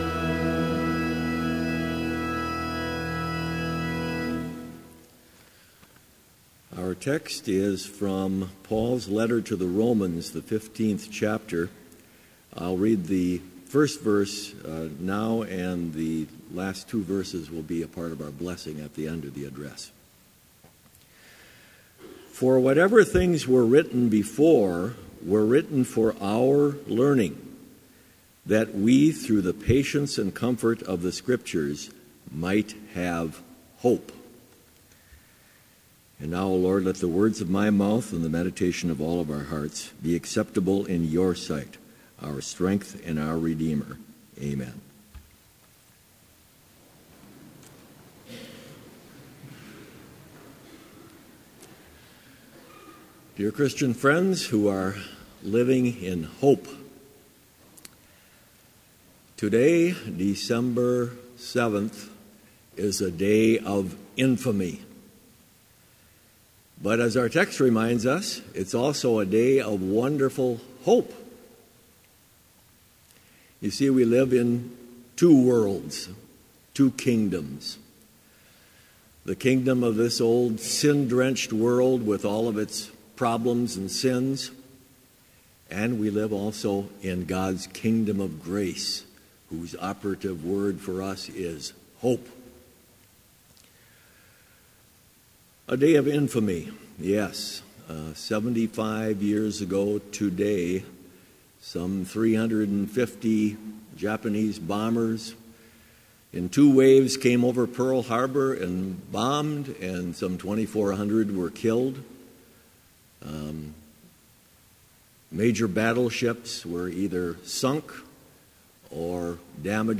Complete service audio for Chapel - December 7, 2016